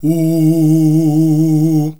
UUUUH   A#.wav